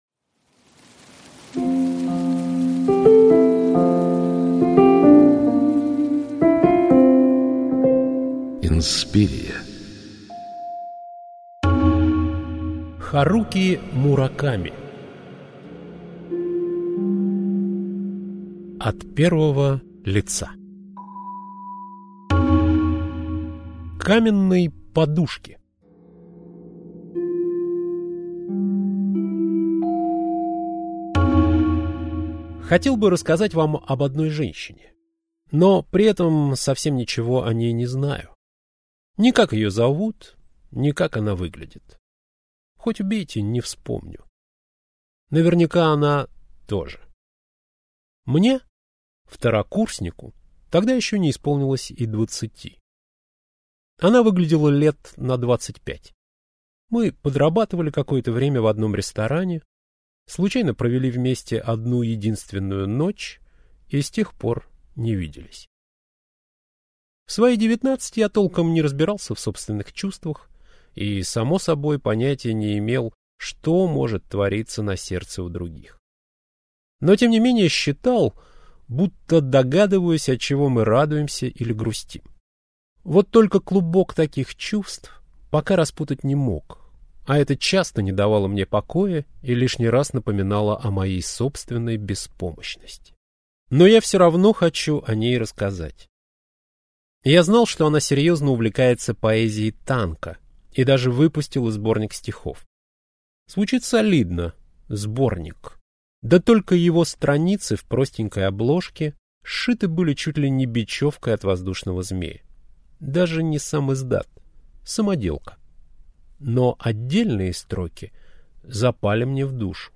Аудиокнига От первого лица - Мураками Харуки - Скачать книгу, слушать онлайн